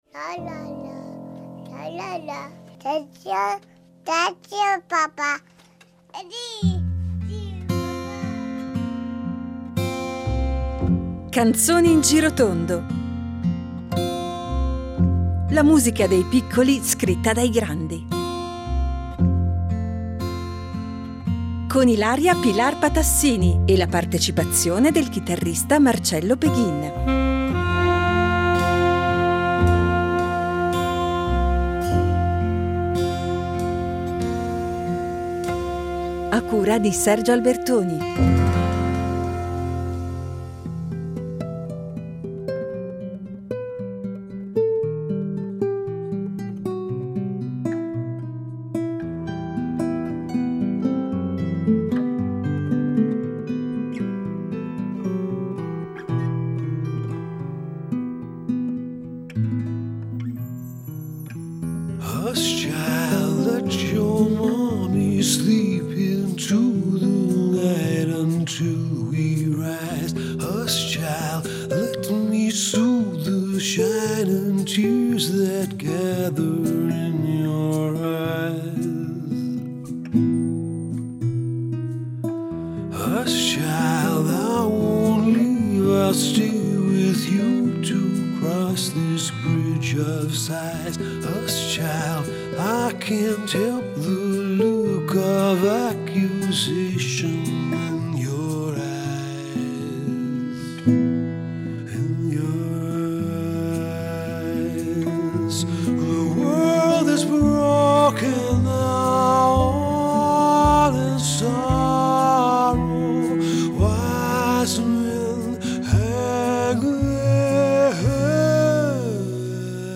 e la partecipazione del chitarrista
Ninne nanne dal mondo